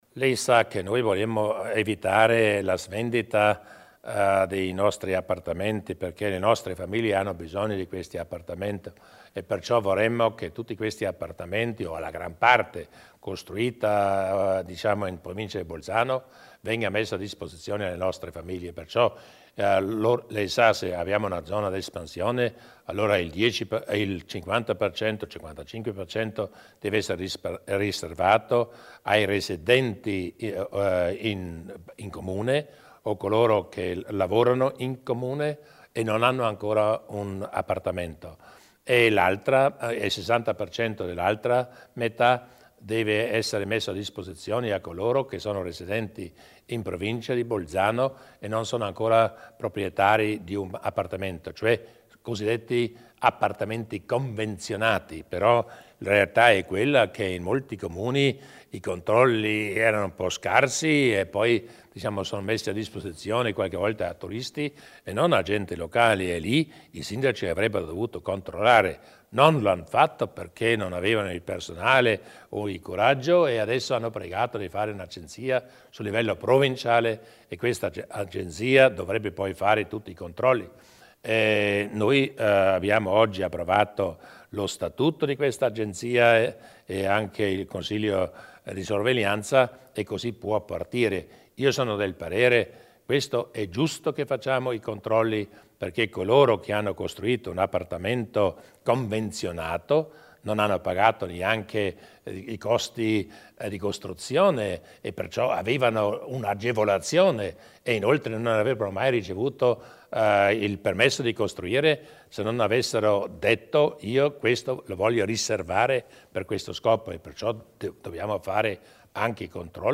Il Presidente Durnwalder illustra le novità per i controlli edili